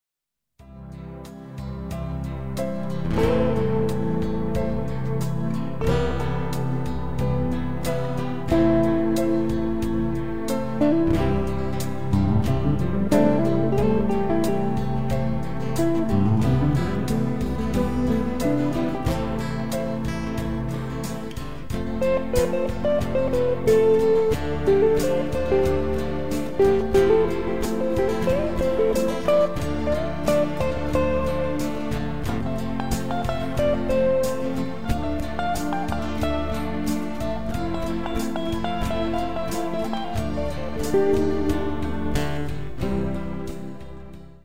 Sie sind zum träumen und entspannen gedacht.